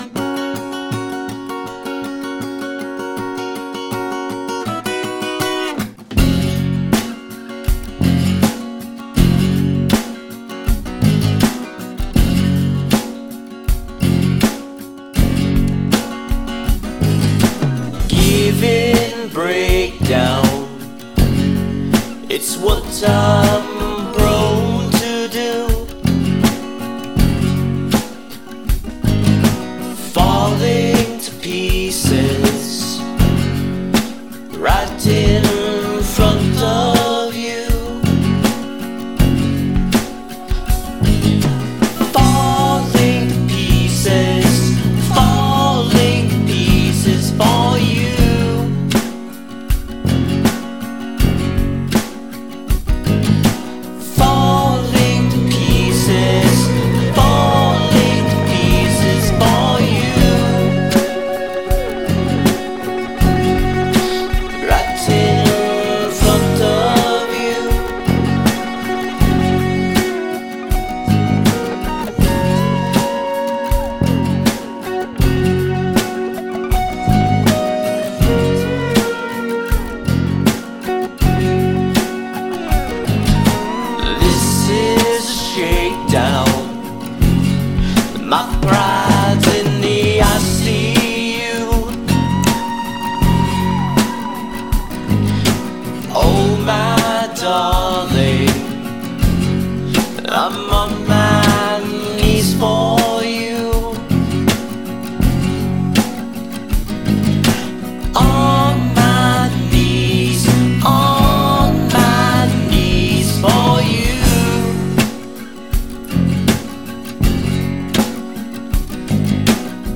I came up with a slightly new way of accompanying the rhythm and I wrote a third verse.